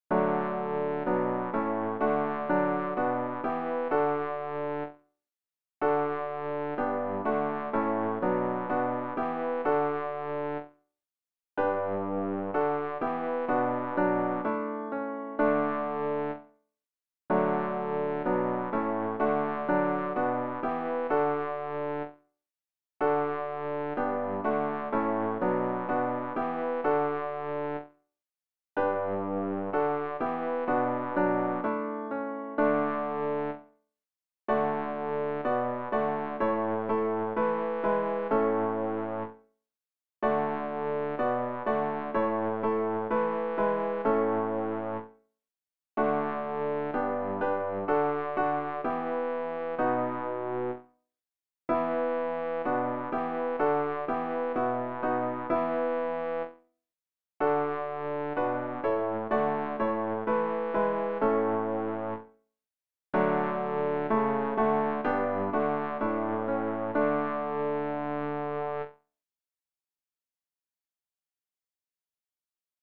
bass-rg-027-o-hoechster-deine-guetigkeit.mp3